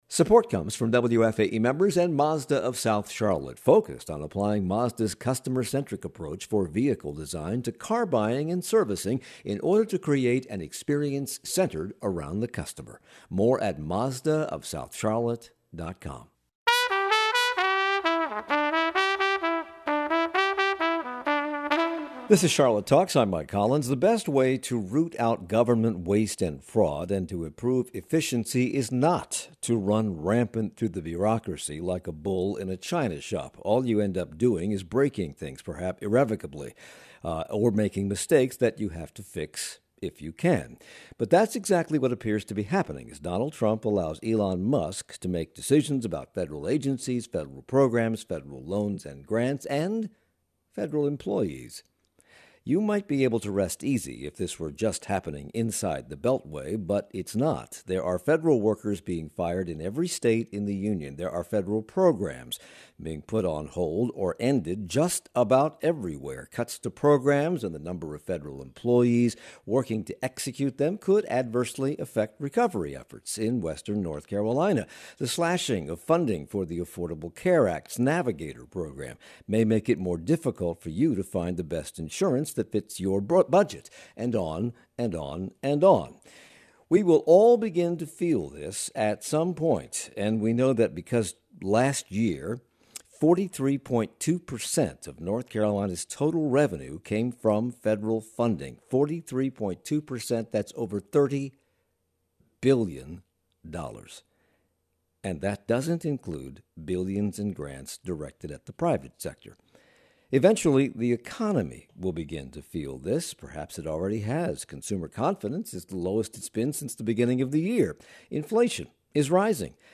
Charlotte NPR Discussion (Audio only)